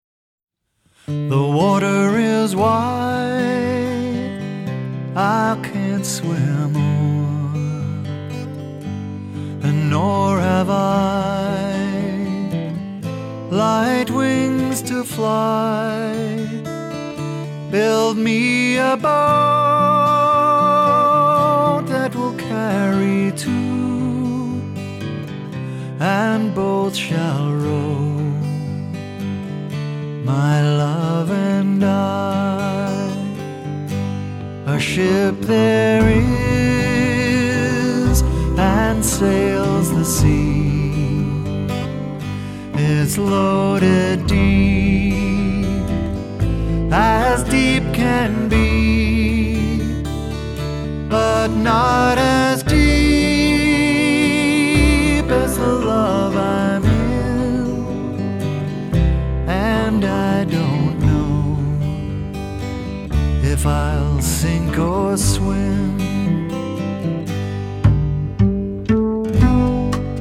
★ 來自澳洲的民謠爵士三人組清新自然的民謠演繹，帶給您舒服暢快的聆聽享受！